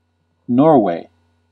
Ääntäminen
Vaihtoehtoiset kirjoitusmuodot (vanhahtava) Norroway Synonyymit Norwegia Ääntäminen US UK : IPA : /ˈnɔː.weɪ/ US : IPA : /ˈnɔɹ.weɪ/ Lyhenteet ja supistumat (laki) Nor.